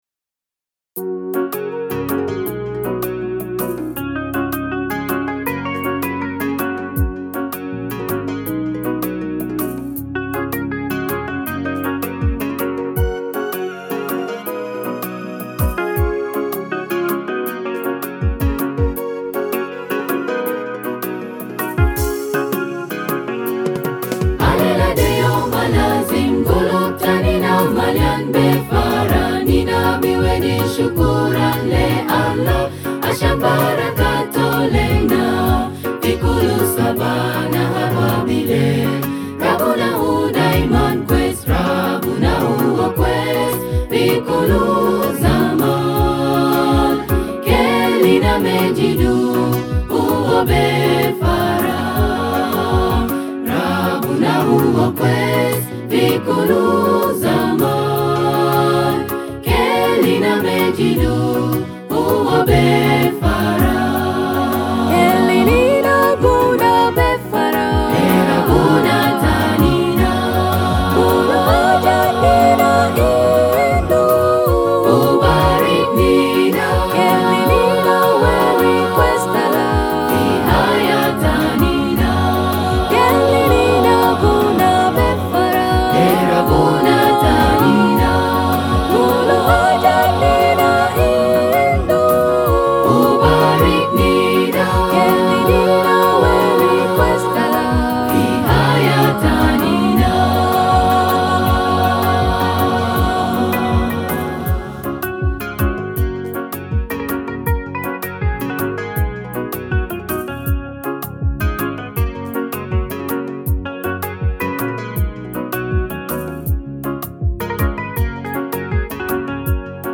is a praise and worship song.